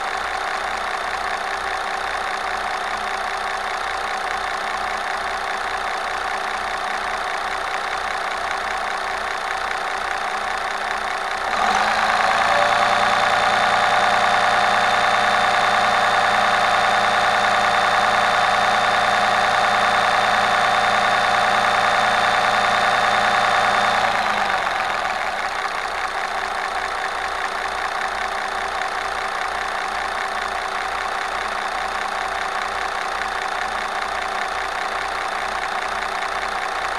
diesel engine noise analysis- developer wanted
I am working on a project where we are using a special pickup microphone to record .wav files of the valvetrain of a diesel engine (running). We are analyzing the audio file to identify if the engine has a problem.